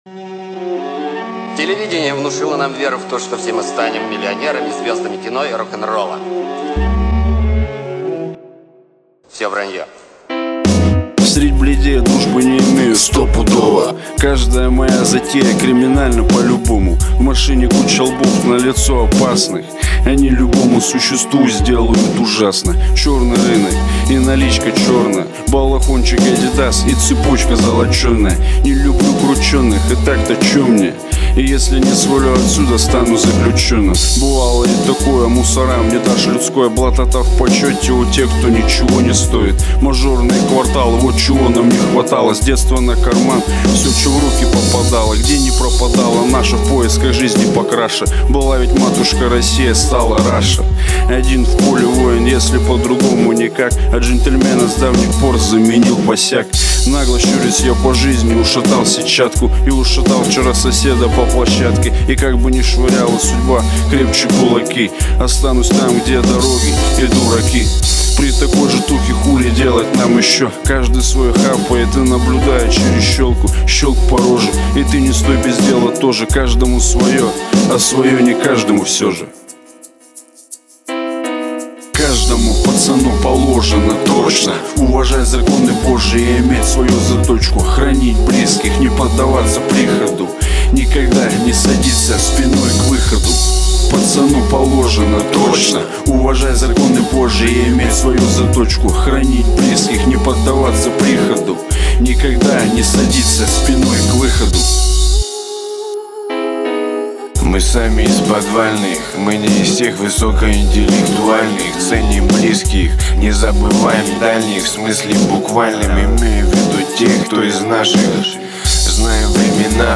Жанр:Рэп